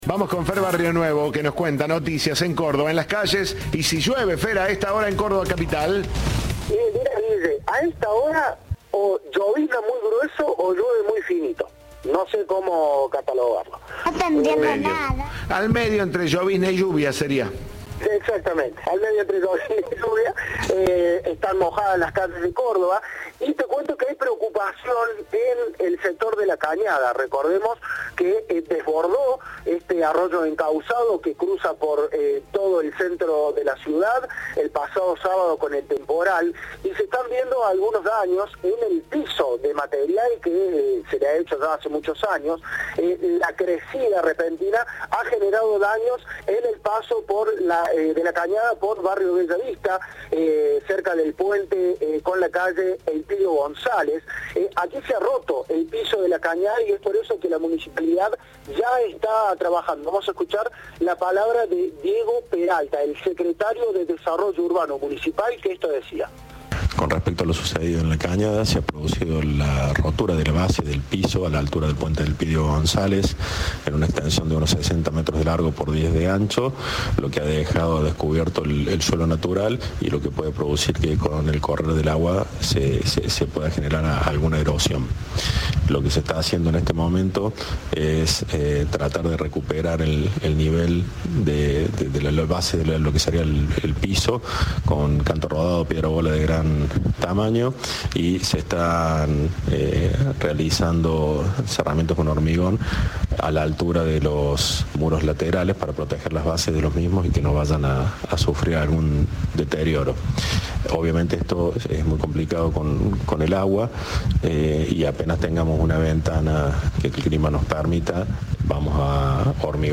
Según indicó a Cadena 3 el secretario de Desarrollo Urbano Municipal, Diego Peralta, se trata de una rotura en la base del piso que tiene una extensión de unos 60 metros de largo y 10 de ancho.